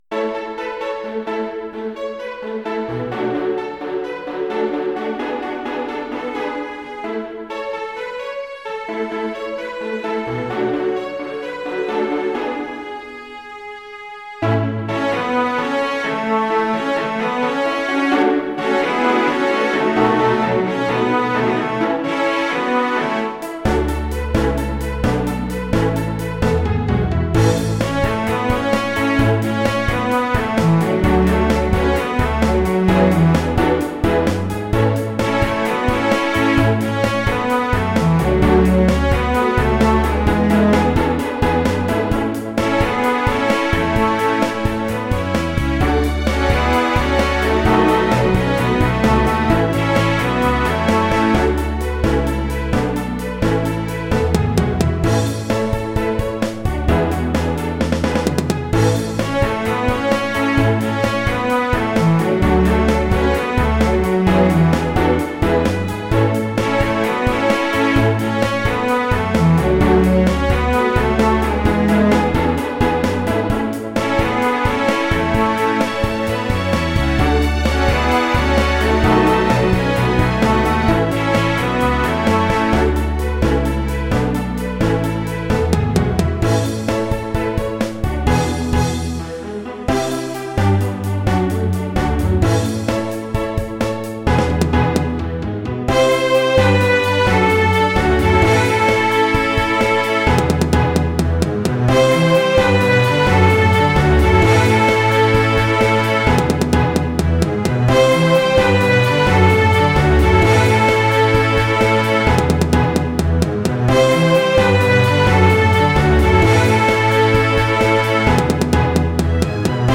Cello Double Bass Tympani
Drums Percussion Tuned Percussion
Piano Harpsichord Hammond Organ
Synthesizer Classical Guitar Electric Guitar